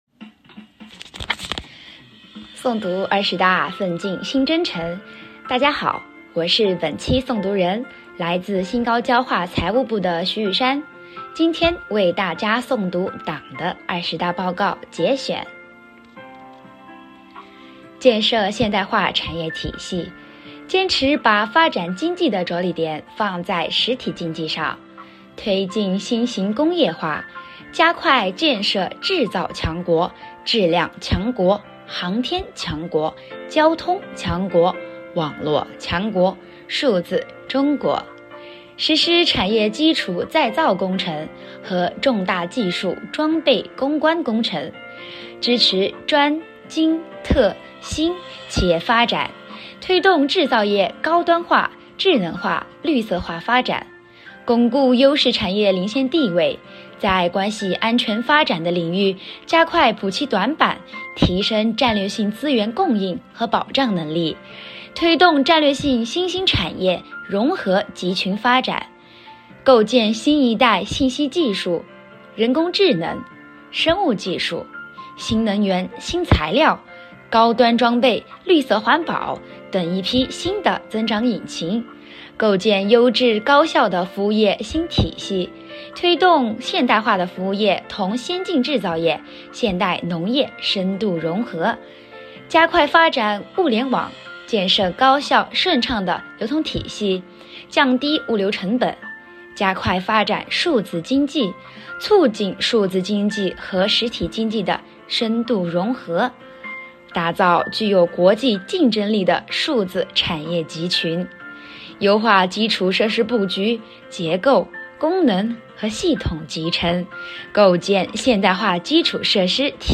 即日起，丰矿集团微信公众号推出“诵读二十大 奋进新征程”专栏，组织党员职工诵读报告原文节选内容，用声音传递力量，真正把党的二十大精神内化于心、外化于行，切实把学习成果转化为工作实绩，转化为引领各项工作创新发展的强大动能，以新时代“第一等”的工作推进丰矿集团实现高质量跨越式发展。
本期诵读